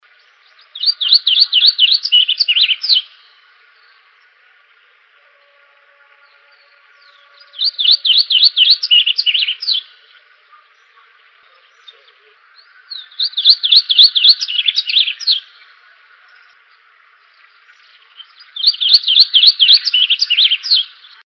Southern Yellowthroat (Geothlypis velata)
Sex: Male
Life Stage: Adult
Location or protected area: Reserva Ecológica Costanera Sur (RECS)
Condition: Wild
Certainty: Photographed, Recorded vocal